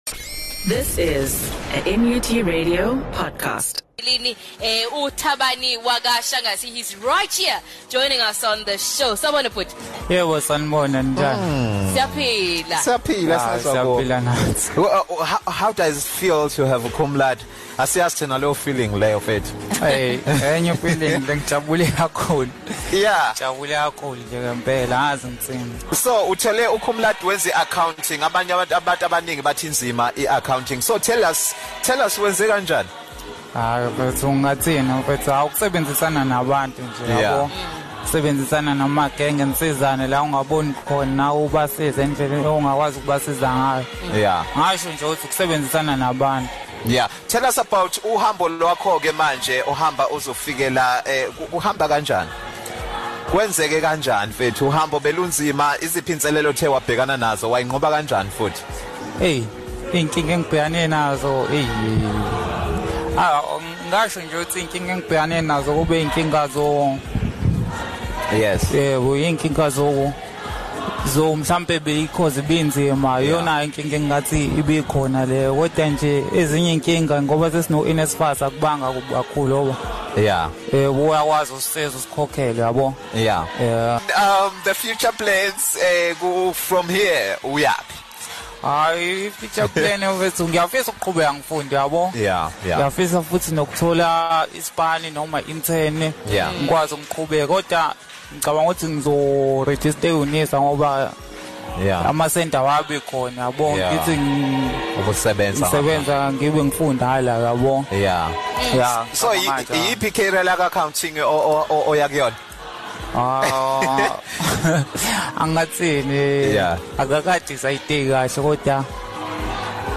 12 Aug The Hlanganani Midday show had an Interview with One of the top sudent.